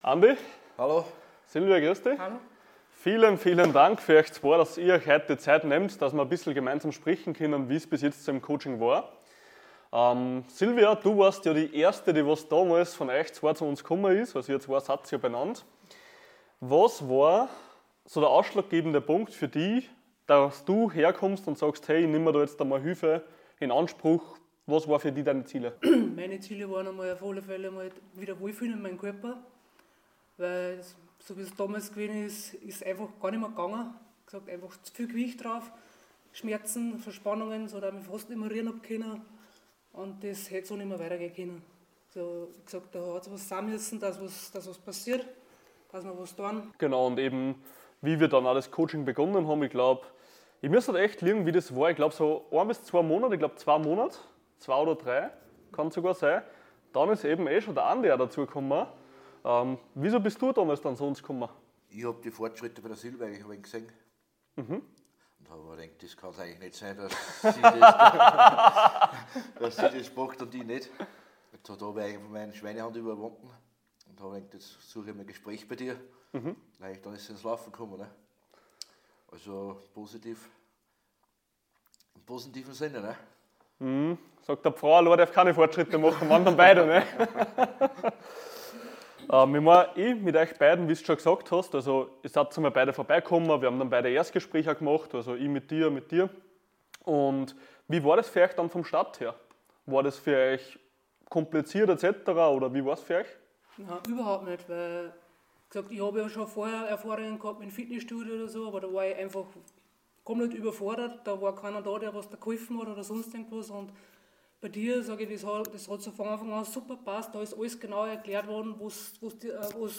Ein kleines Interview